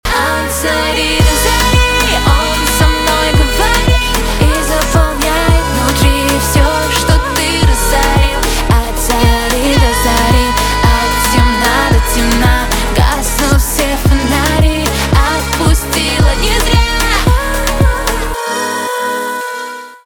танцевальные
поп
битовые , басы